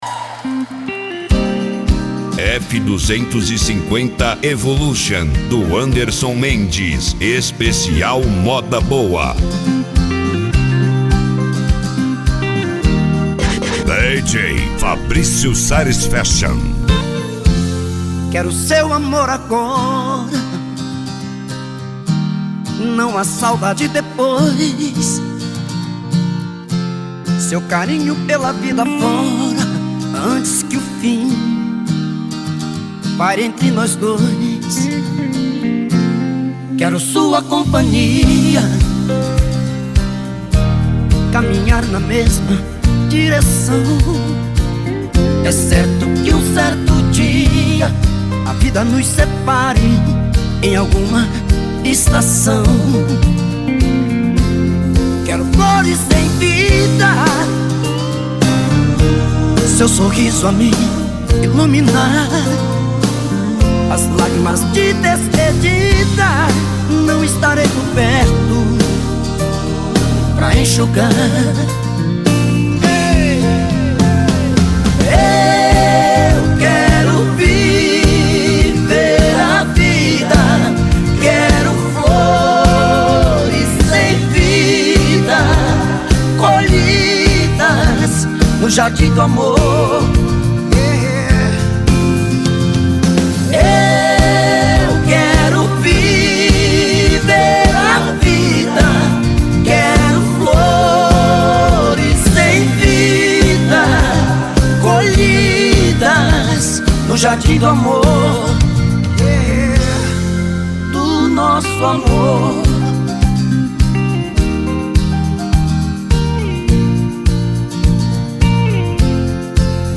Modao
SERTANEJO